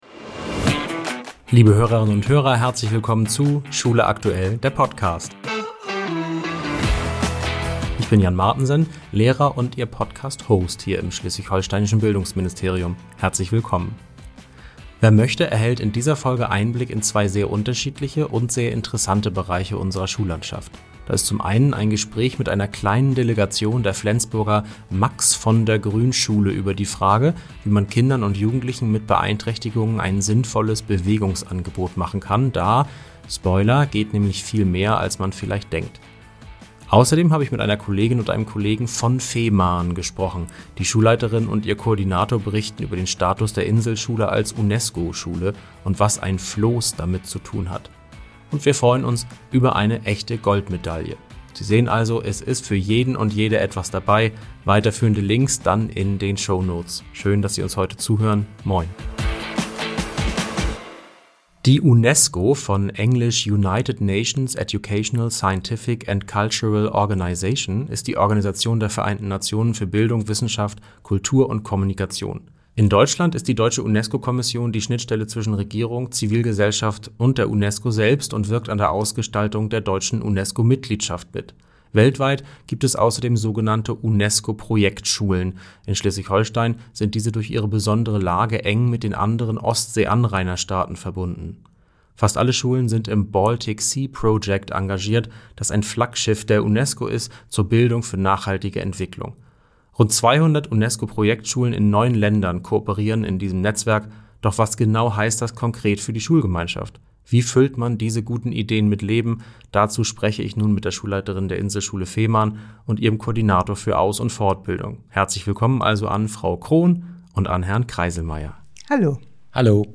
Beschreibung vor 1 Jahr Sie hören heute ein Gespräch mit einer kleinen Delegation der Flensburger Max-von-der-Grün-Schule über die Frage, wie man Kindern und Jugendlichen mit Beeinträchtigungen ein sinnvolles und nachhaltiges Bewegungsangebot machen kann.